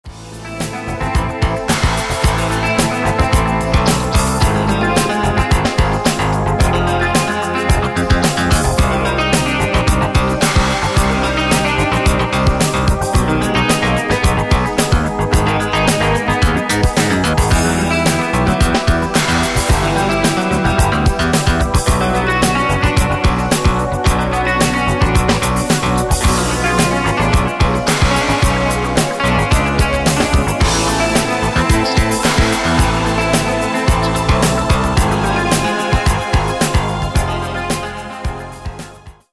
2002 Versione più ritmata dell’omonimo brano.